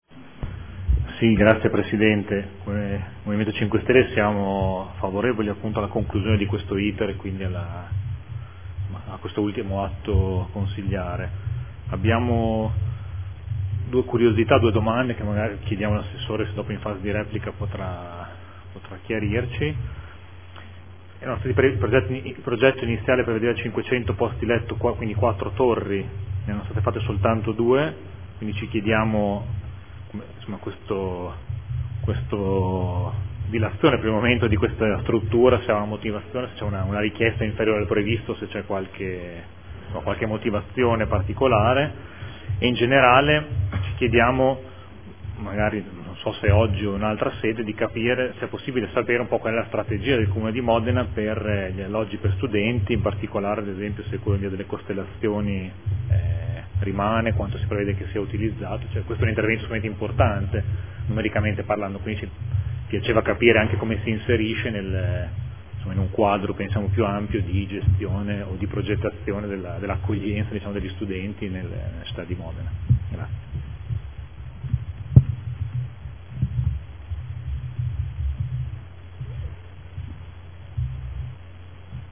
Mario Bussetti — Sito Audio Consiglio Comunale
Seduta del 09/03/2015 Proposta di deliberazione: Concessione di lavori pubblici per la costruzione e gestione di alloggi per studenti e personale universitario a canone concordato denominata “Campus Campi” – Promessa di cessione di quote di proprietà superficiaria a UNIMORE – Università degli Studi di Modena e Reggio Emilia. Dibattito